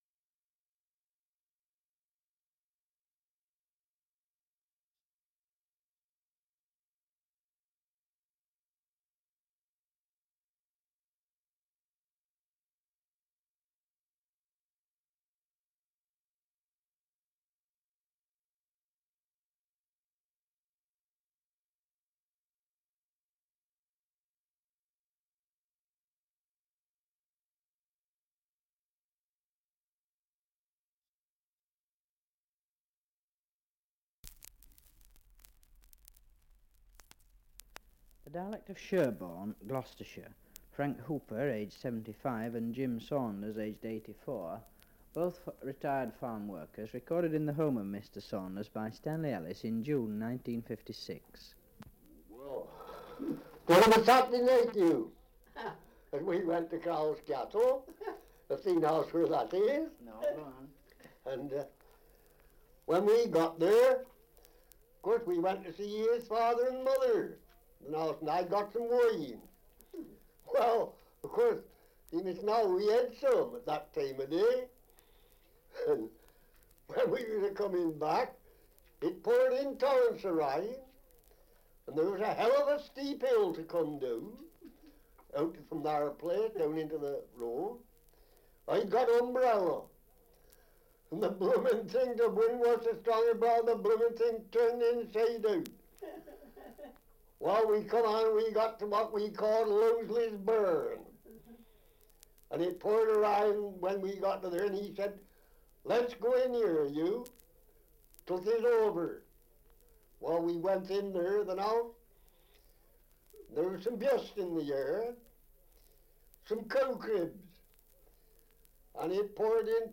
Survey of English Dialects recording in Sherborne, Gloucestershire
78 r.p.m., cellulose nitrate on aluminium